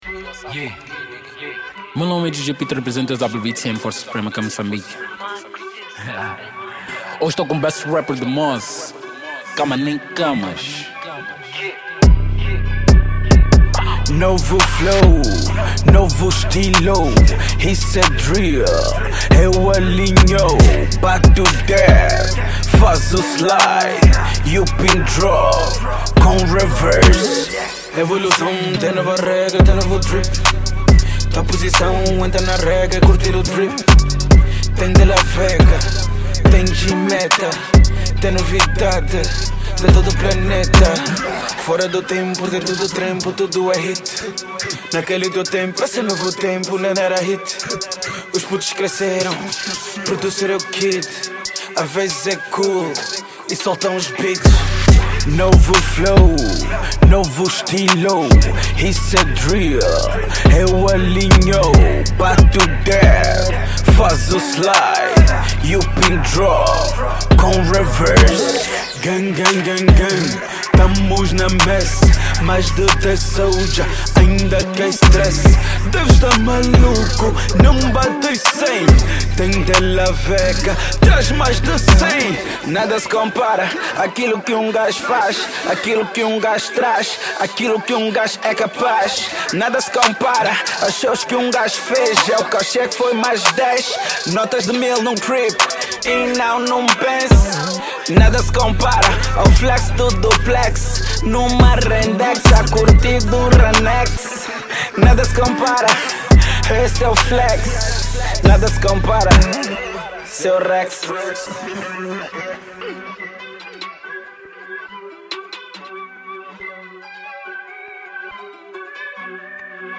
Rap
” Kizomba 2021 ”Download Mp3” 320kbps